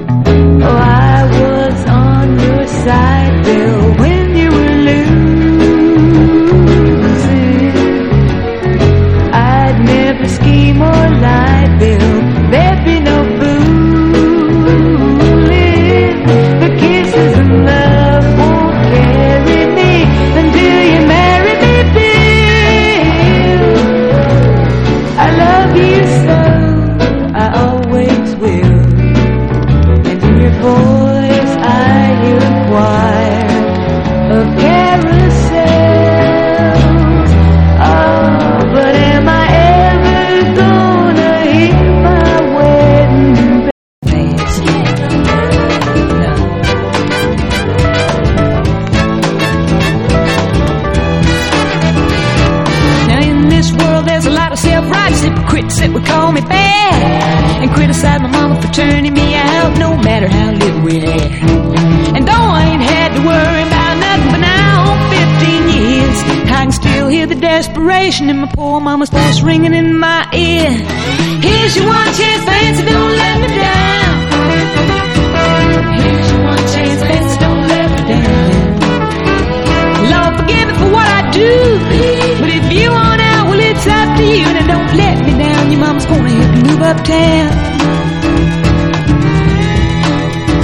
EASY LISTENING / VOCAL / BRITISH BEAT
ライヴ・ヴァージョン！ 原曲よりも上げ上げでノリノリな